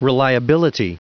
Prononciation du mot reliability en anglais (fichier audio)
Prononciation du mot : reliability